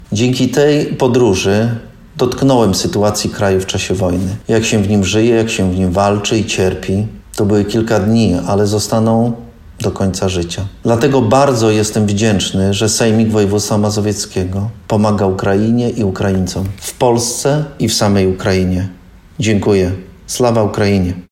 Swoją wdzięczność wyraził radny sejmiku województwa mazowieckiego Piotr Kandyba, który niedawno odwiedził Ukrainę: